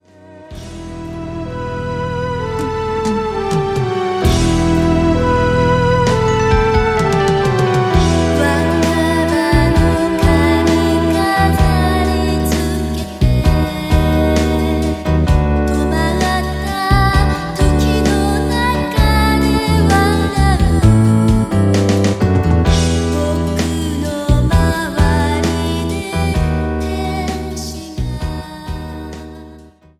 Progressive
Symphonic